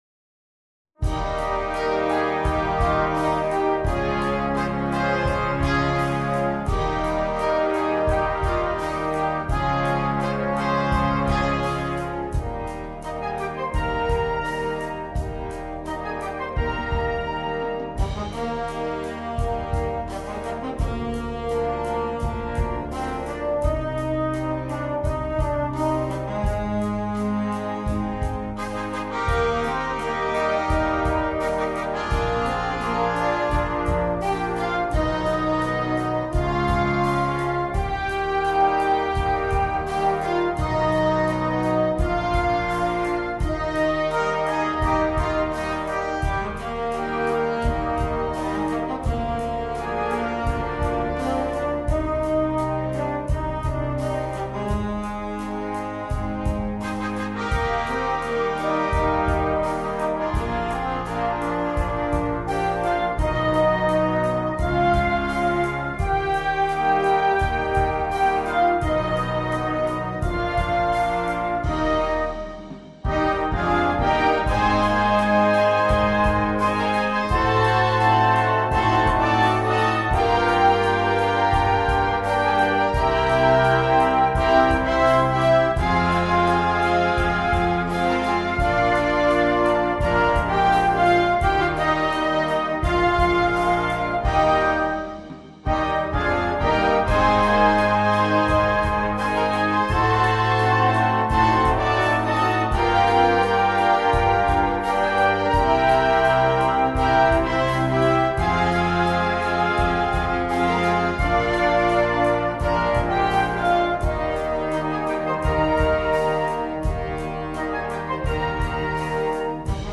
for band